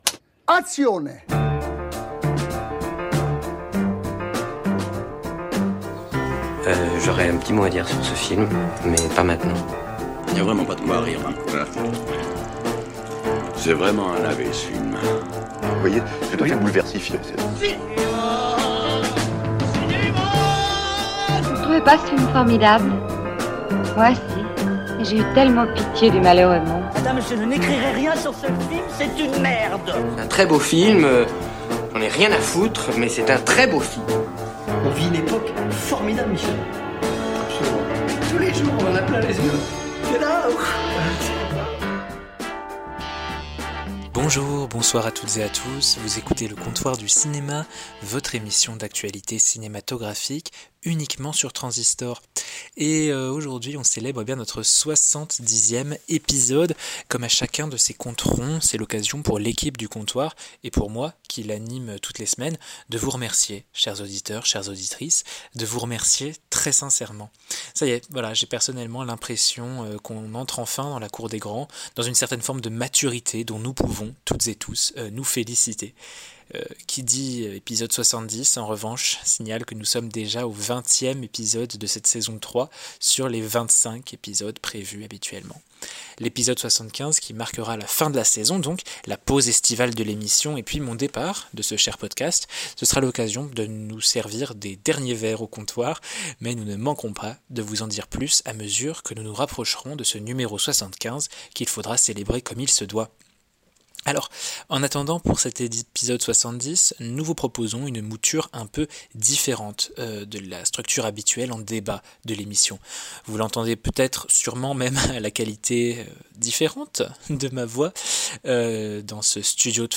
Émission enregistrée hors du studio et au studio de Trensistor, le 10 mars 2026.